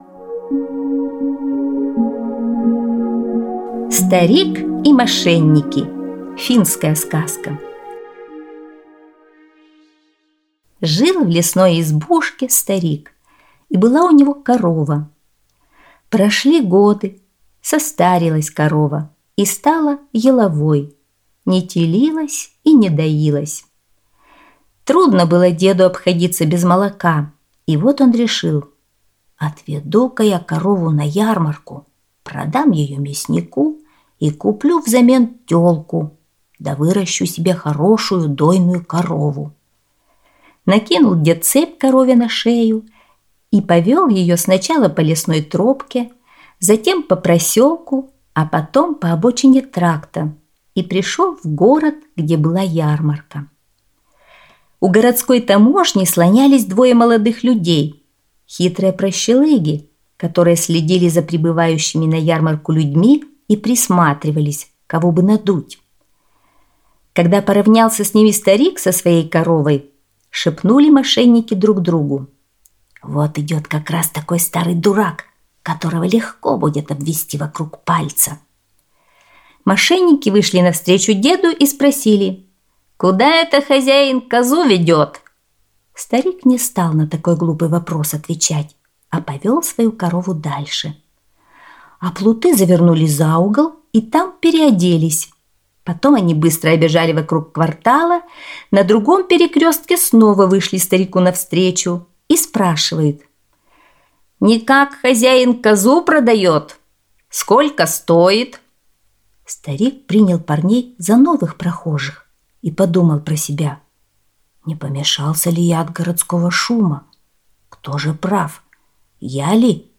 Старик и мошенники - финская аудиосказка - слушать онлайн